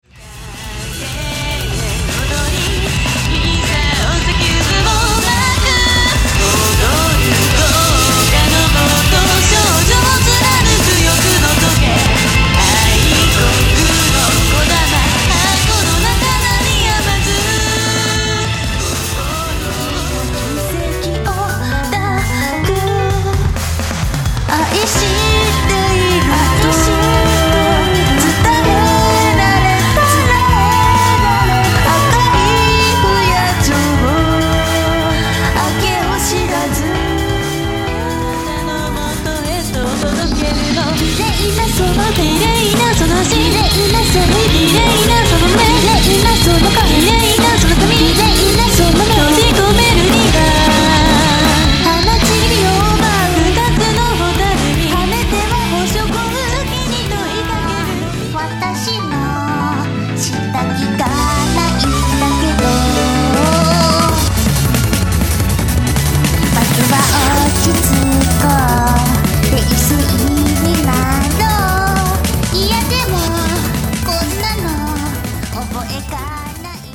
東方ボーカルアレンジCD
ロックからジャズ、ピコピコ電波系までを